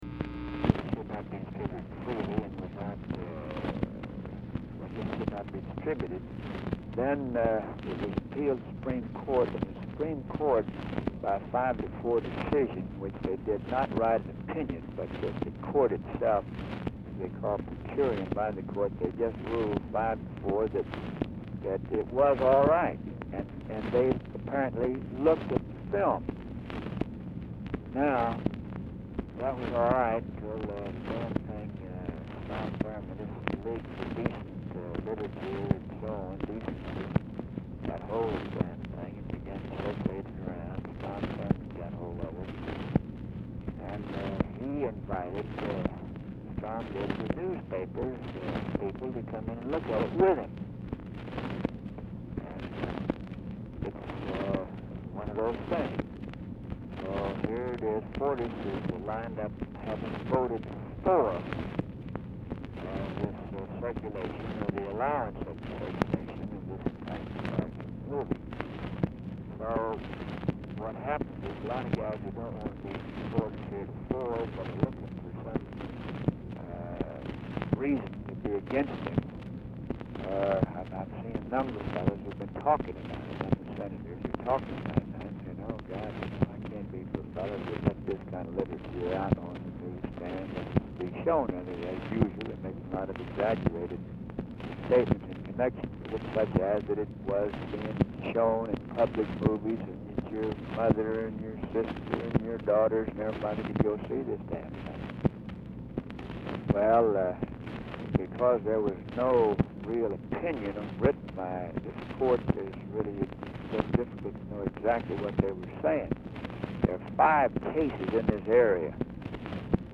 RECORDING STARTS AFTER CONVERSATION HAS BEGUN; POOR SOUND QUALITY; SMATHERS IS DIFFICULT TO HEAR
Format Dictation belt
Location Of Speaker 1 Mansion, White House, Washington, DC
Specific Item Type Telephone conversation